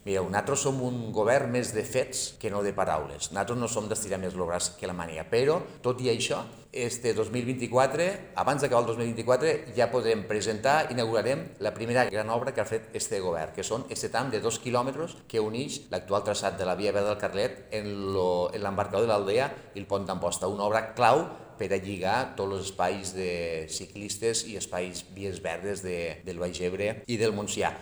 El president del Consell Comarcal, Toni Gilabert, ha destacat que el pressupost inclou també les xifres dels organismes adscrits, com el Baix Ebre Innova amb més de 400.000 euros, el Consorci per al Desenvolupament del Baix Ebre i Montsià (CODEBEM) amb mes de 200.000 euros, i el Consorci de Polítiques Ambientals de les Terres de l’Ebre (COPATE), que compta amb més de 22 milions d’euros.